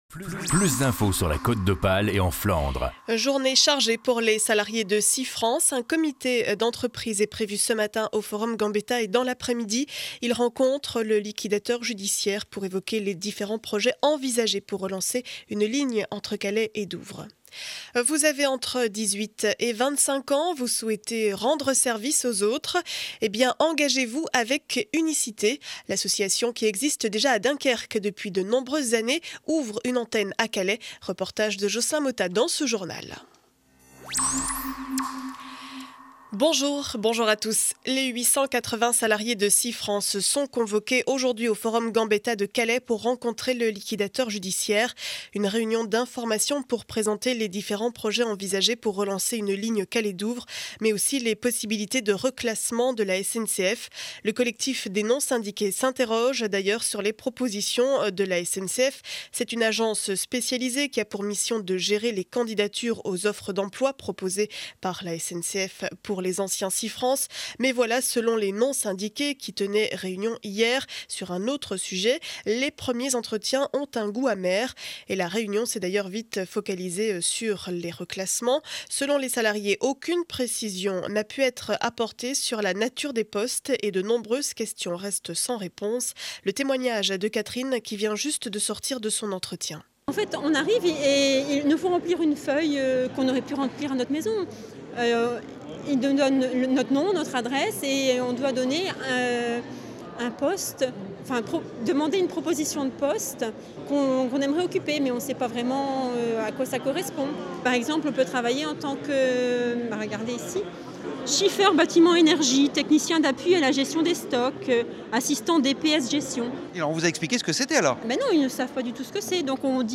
Journal du jeudi 19 janvier 7 heures 30 édition du Calaisis.